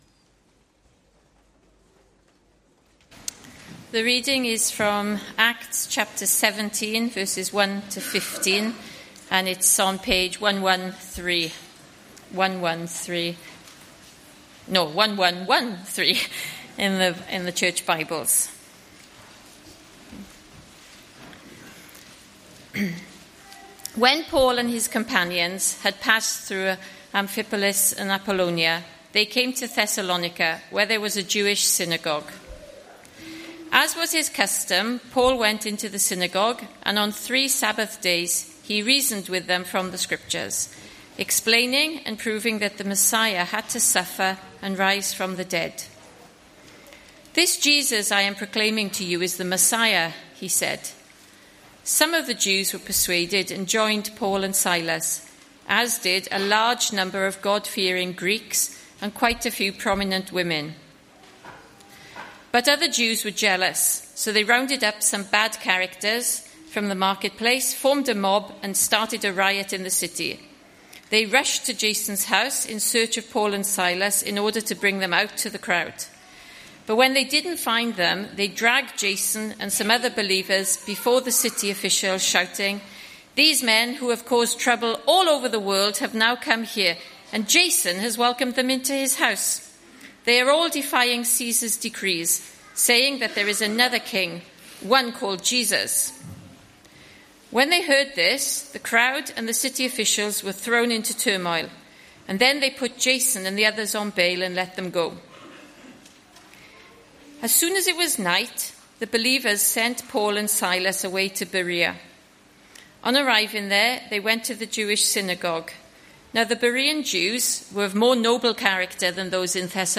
Acts 17:1-15; 11 August 2024, Morning Service.